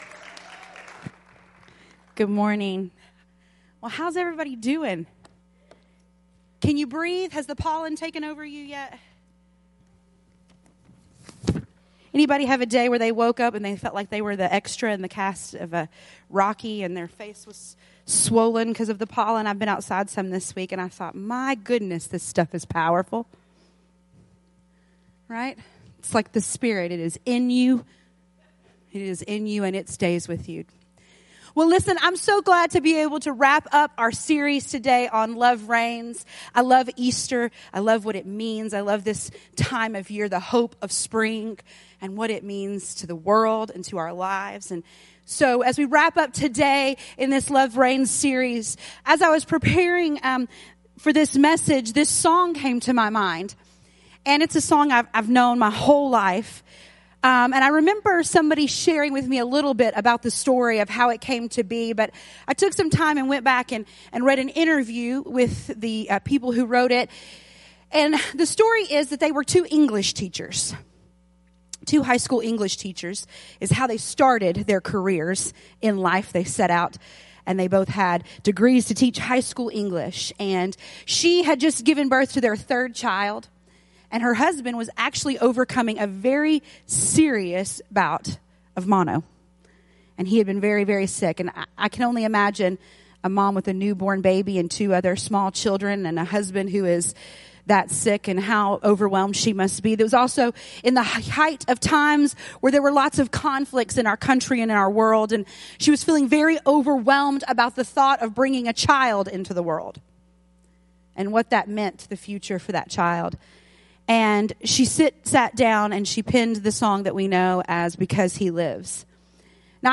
New Life Church Sermons